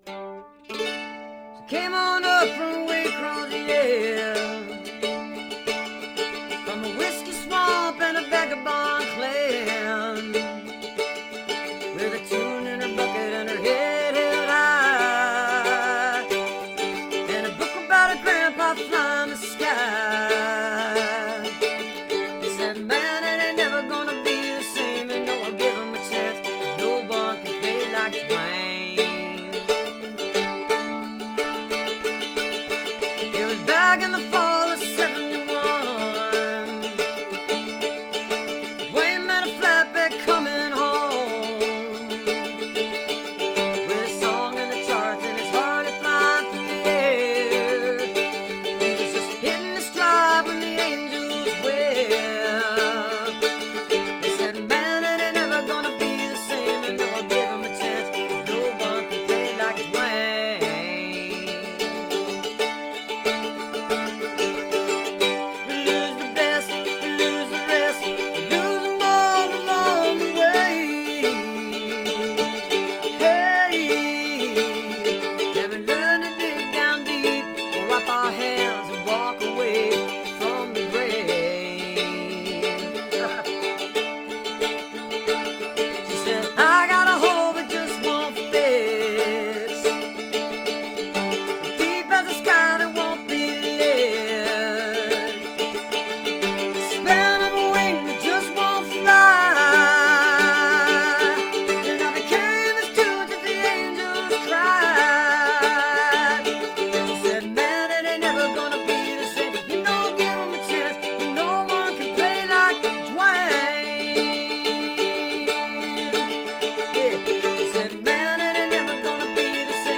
(captured from facebook)